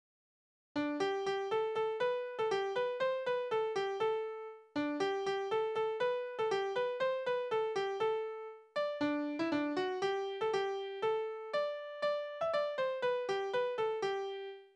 Liebeslieder:
Tonart: G-Dur
Taktart: 2/4
Tonumfang: große None
Besetzung: vokal